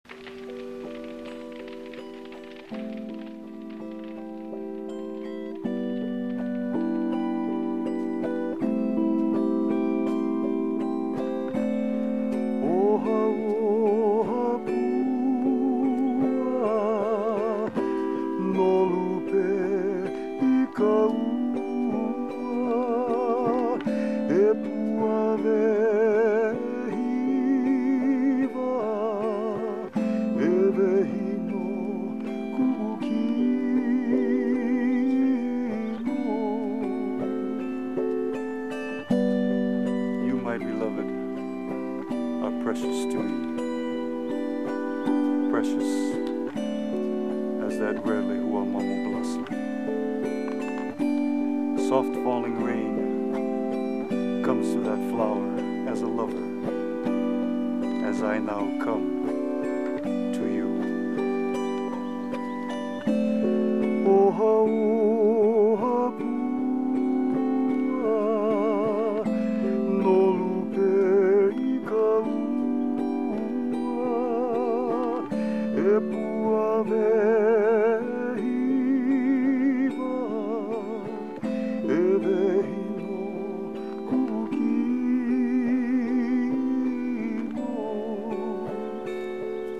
Autoharp
traditional Hawaiian dancing